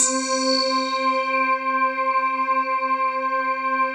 PAD 44-2  -LR.wav